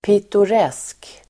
Ladda ner uttalet
Uttal: [pitor'es:k]